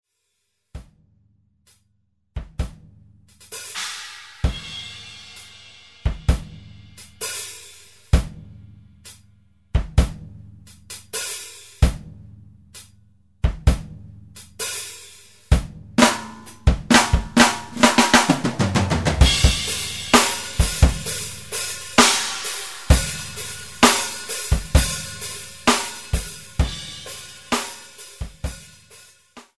kleiner Ausschnitt der noch nackten Drumspur.
drums_snip.mp3